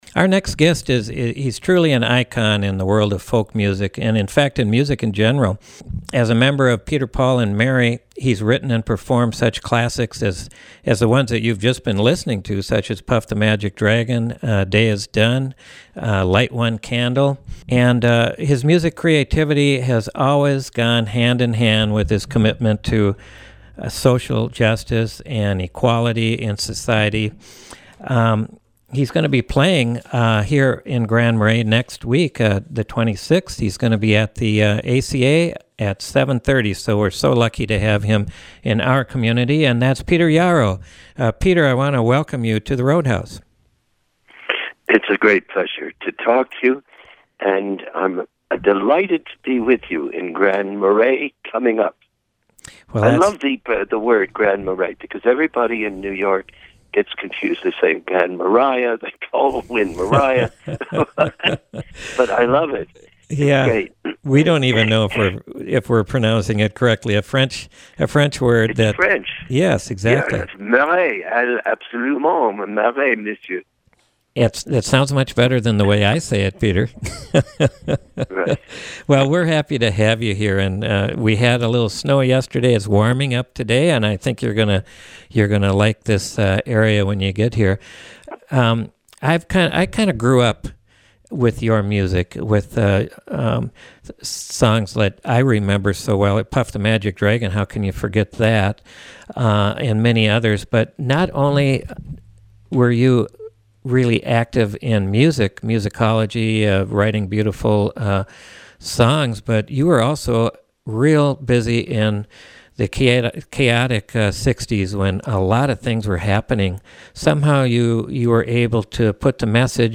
Folk singer & activist Peter Yarrow visits The Roadhouse